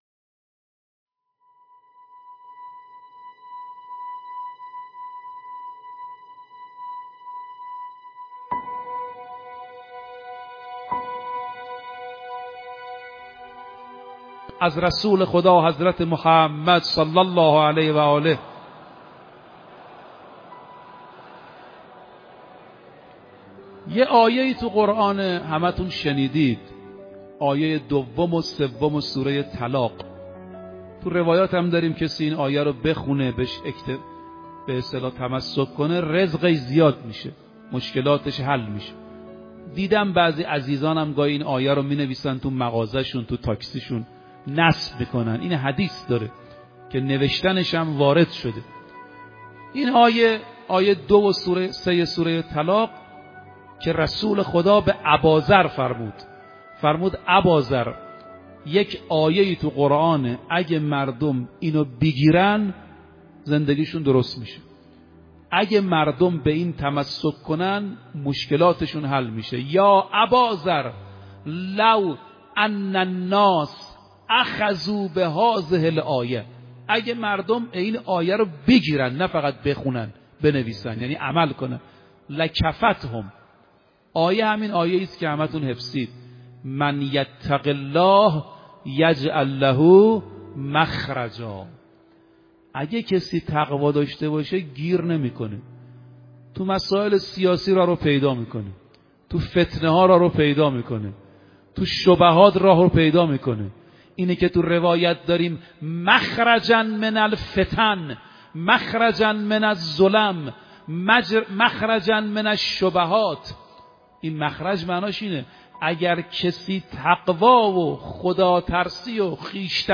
آخرین خبر/ این آیه را برای رزق و روزیِ زیاد بخوانید‌. سخنرانی